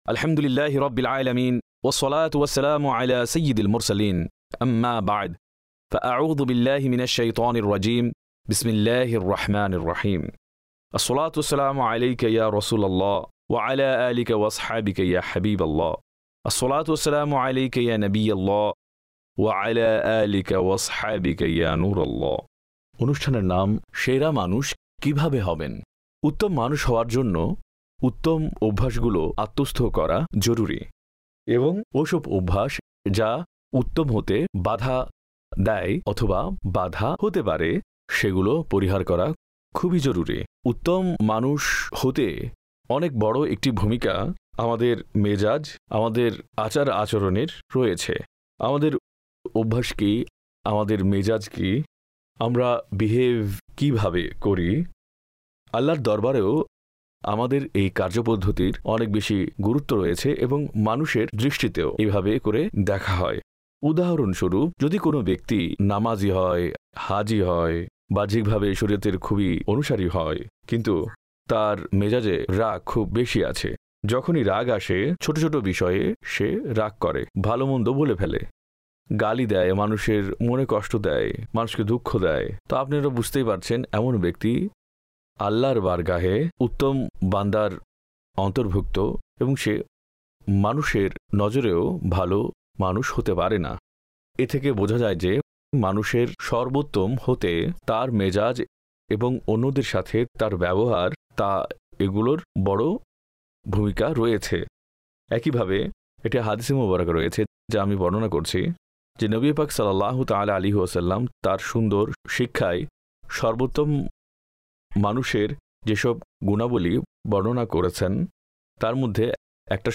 সেরা মানুষ কীভাবে হবেন? ( বাংলায় ডাবিংকৃত ) EP# 5